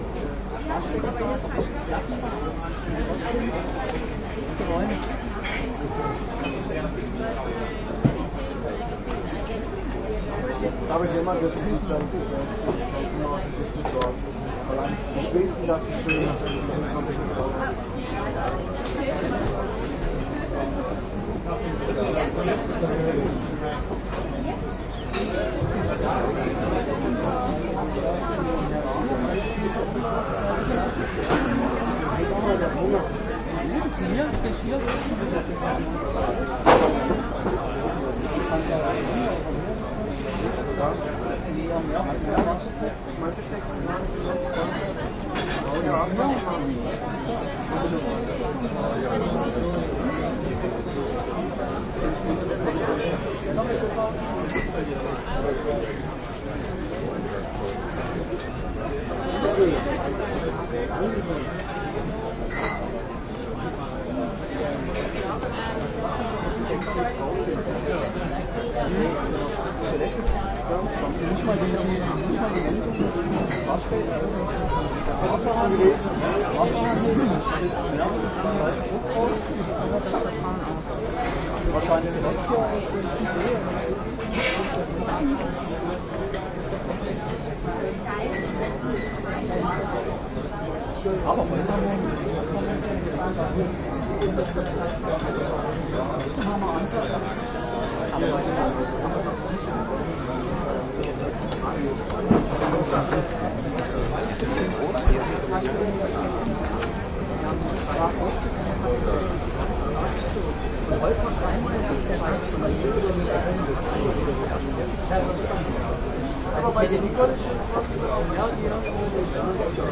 背景音乐 " 啤酒花园
描述：周六下午在Schleusenkrug，柏林蒂尔加滕的“啤酒花园”。他们到处都是麻雀，但你没有在录音中听到它们，使用PCM M10录音机。录音机的“视图”：
Tag: 喋喋不休 现场recordering Schleusenkrug 柏林 酒吧 啤酒花园 人群